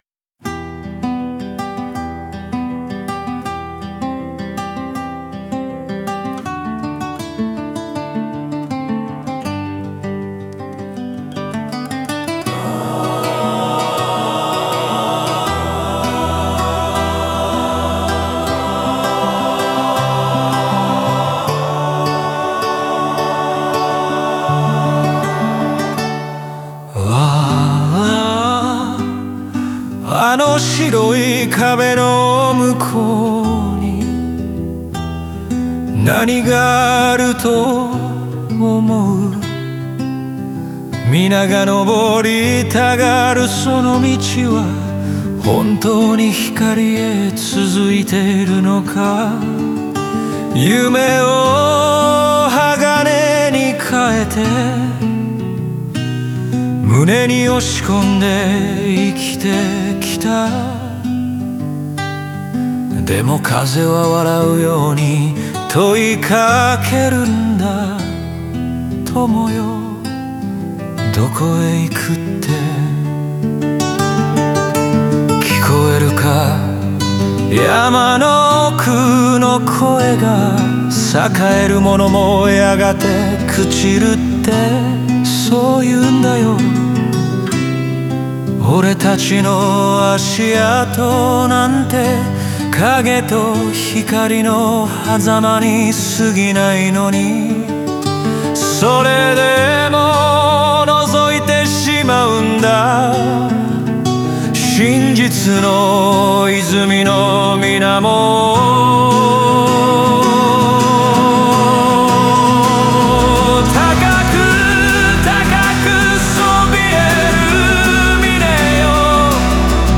オリジナル曲♪
歌い手は独白的に語りかけながら、自身の夢や欲望が果たして何を残すのかを問い続けます。